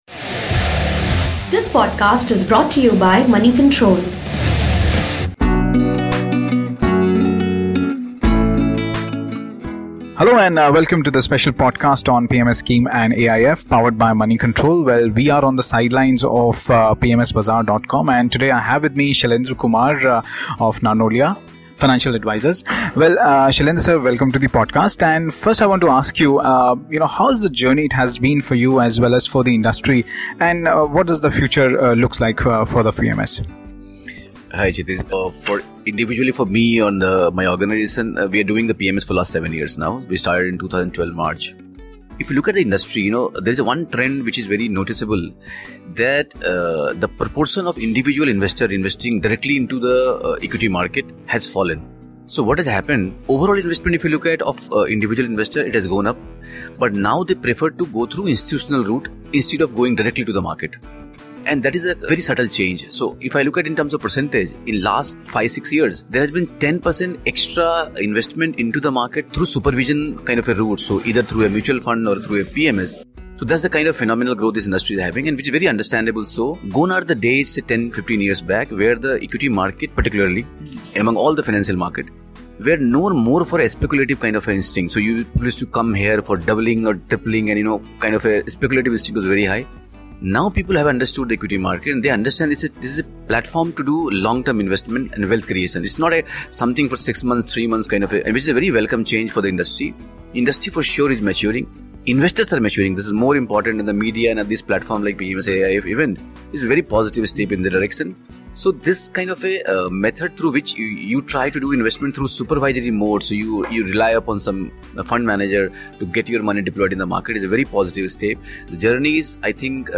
Speaking to Moneycontrol on the sidelines of PMS Bazaar PMS-AIF summit in Mumbai on 18 October